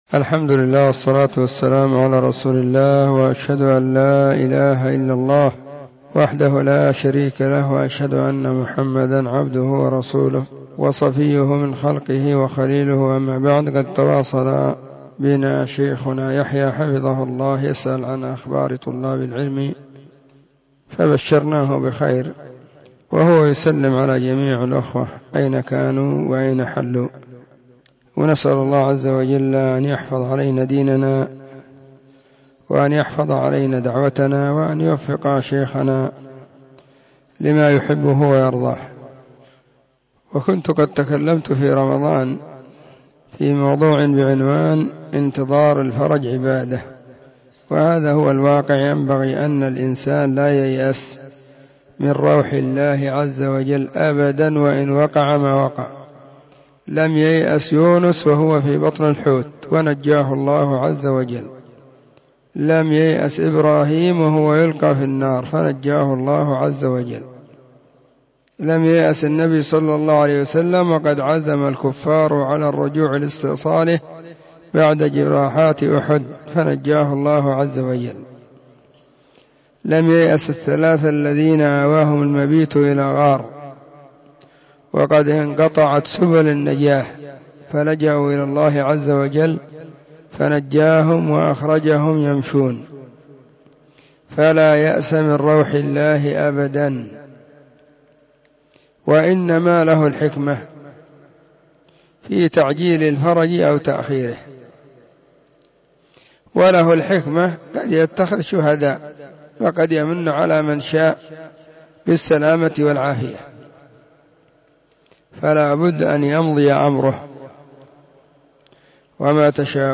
🎙 كلمة قيمة بعنوان: *💿انتظار الفرج عبادة💿*
📢 مسجد – الصحابة – بالغيضة – المهرة، اليمن حرسها الله.